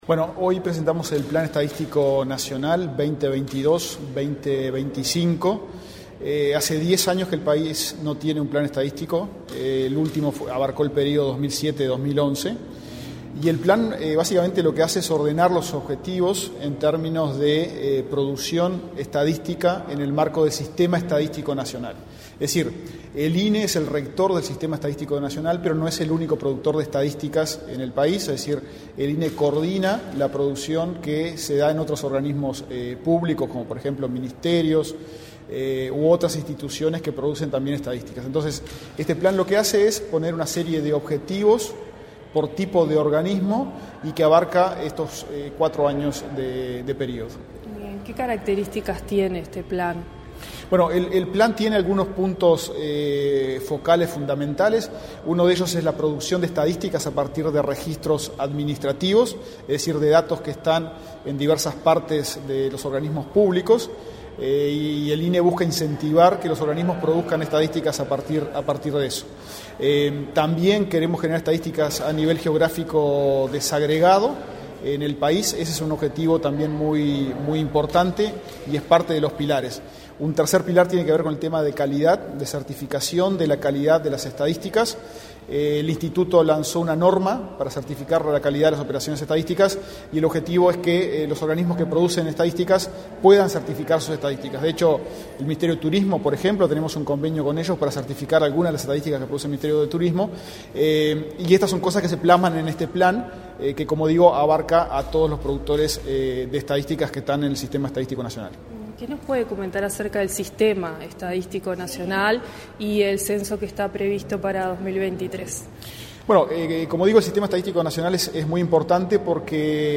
Entrevista al presidente del INE, Diego Aboal
El presidente del INE, en declaraciones a Comunicación Presidencial, explicó la iniciativa.